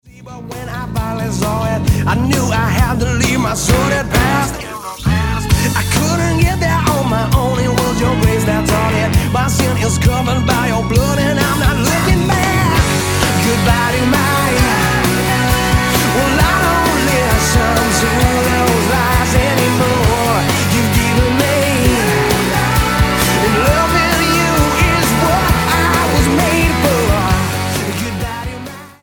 Rock Album